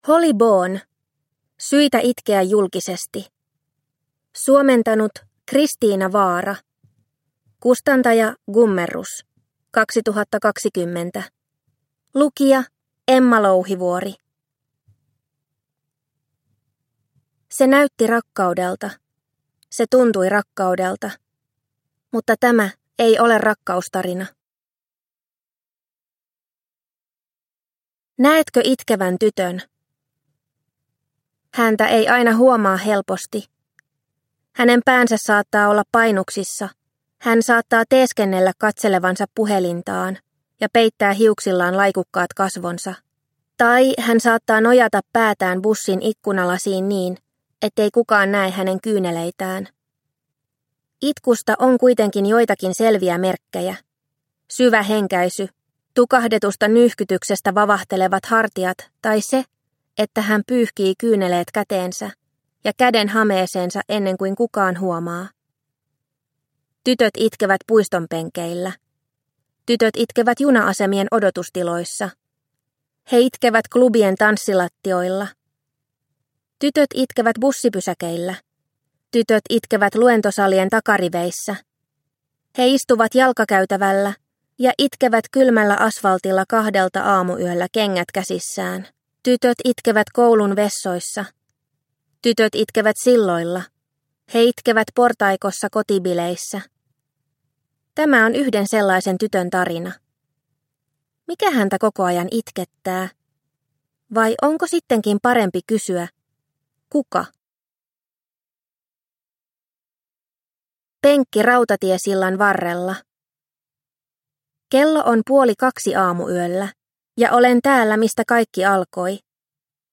Syitä itkeä julkisesti – Ljudbok – Laddas ner